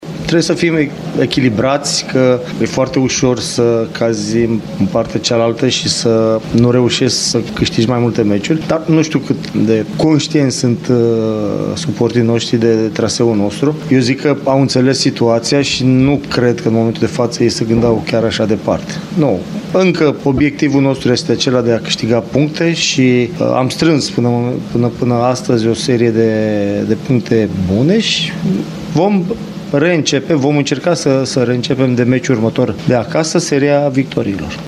Antrenorul Adrian Mihalcea spune că atât echipa, cât și suporterii trebuie să rămână echilibrați și să gestioneze eficient primul eșec al sezonului: